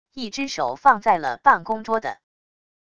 一只手放在了办公桌的wav音频